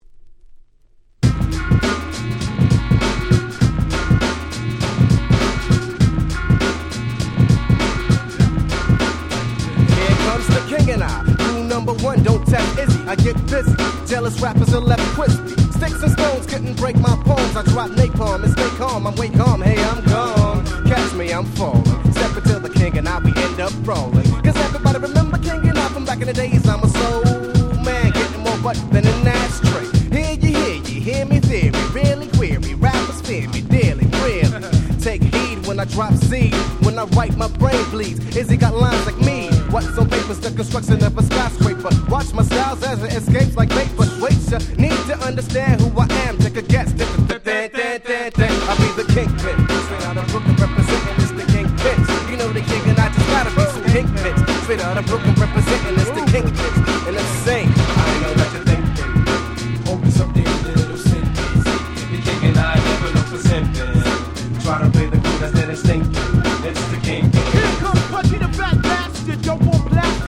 93' Super Nice Hip Hop / Boom Bap !!